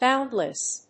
音節bóund・less 発音記号・読み方
/ˈbaʊndlʌs(米国英語)/